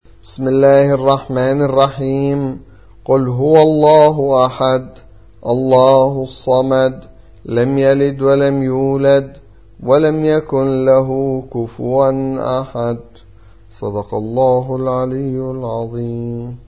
112. سورة الإخلاص / القارئ